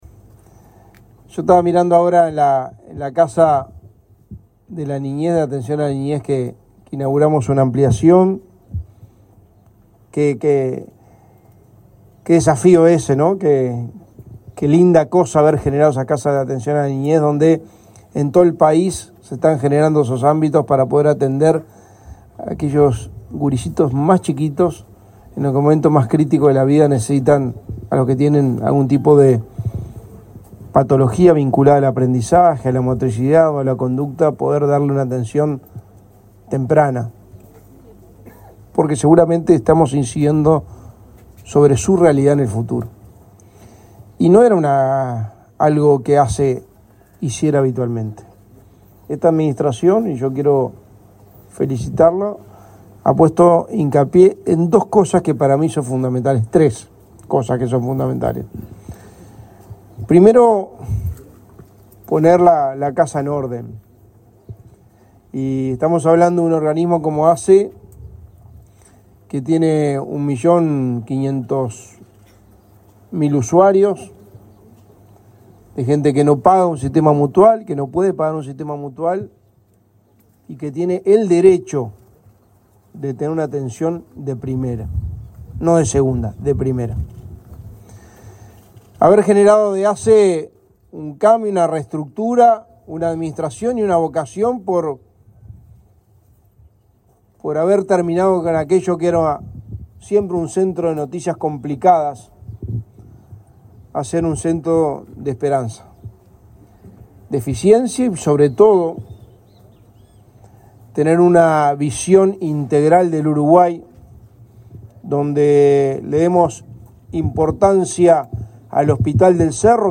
Palabras del secretario de Presidencia, Álvaro Delgado
Durante una recorrida de ASSE por el departamento de Rivera, donde inauguró nuevos servicios de salud, el secretario de Presidencia, Álvaro Delgado,